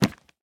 Minecraft Version Minecraft Version snapshot Latest Release | Latest Snapshot snapshot / assets / minecraft / sounds / block / chiseled_bookshelf / pickup2.ogg Compare With Compare With Latest Release | Latest Snapshot
pickup2.ogg